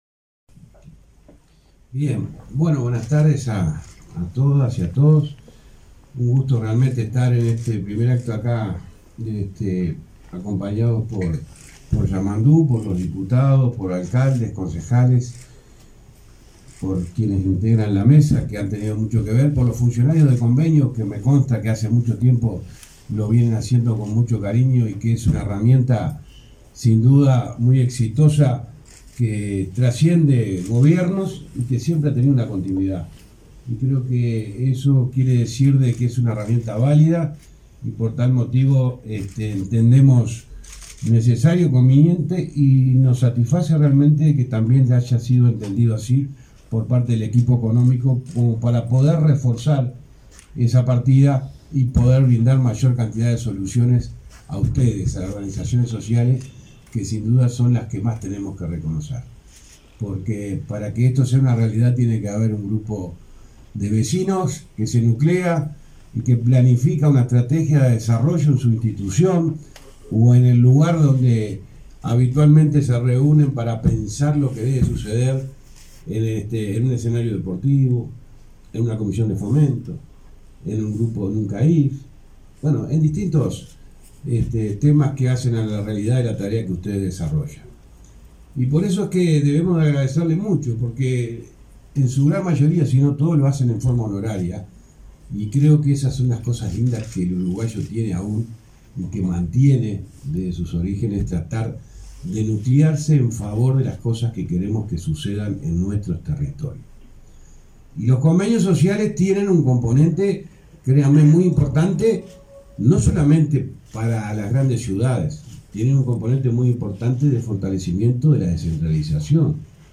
Conferencia de prensa de autoridades del MTOP e Intendencia de Canelones
Participaron en el acto el ministro José Luis Falero; el director nacional del Arquitectura, Santiago Borsari; y el intendente de Canelones, Yamandú Orsi.